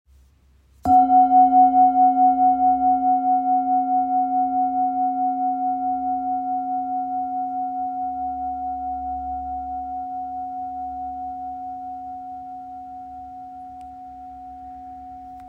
Handcrafted by skilled artisans, each bowl is designed for both striking and singing, producing clear, harmonious sounds with a wide range of tones, long-lasting vibrations, and stable overtones.